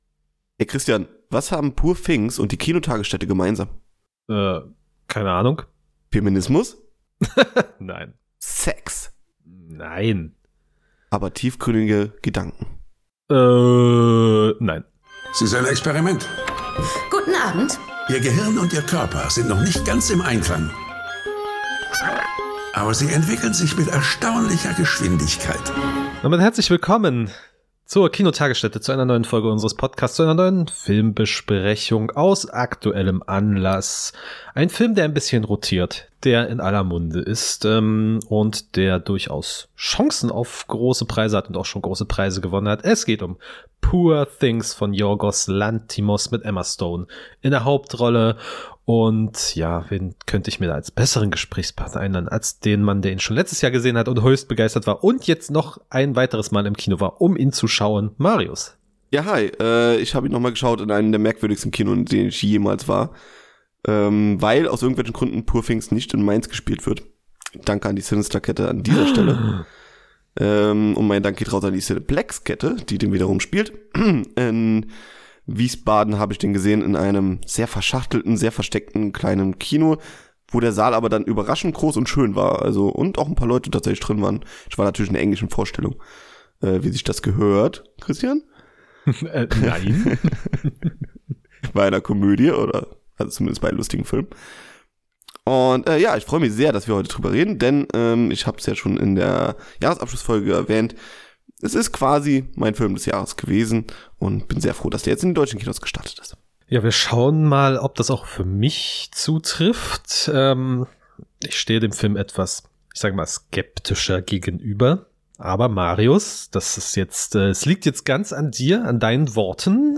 poor_things_review_talk.mp3